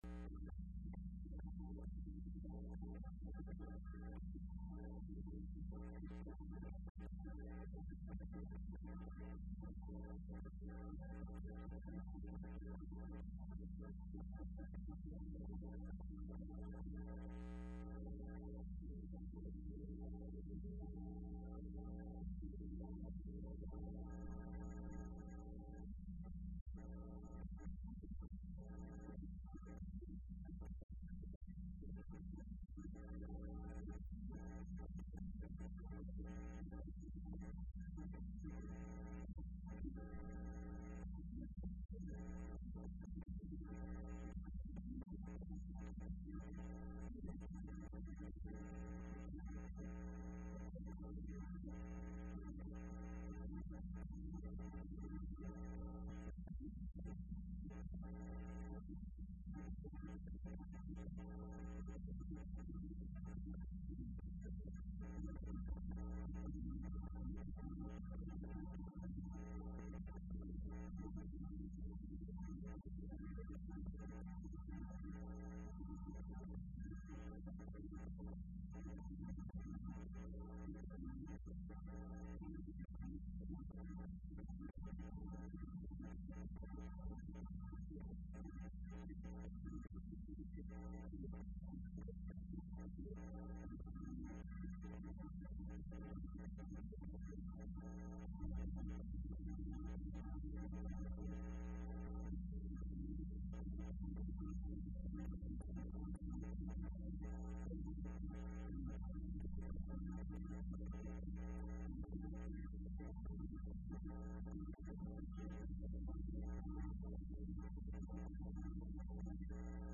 Entrevista Opinión Universitaria(10 de Noviembre 2015): Proyecto de gestión 2015-2019 para Facultad de Medicina por sus autoridades locales.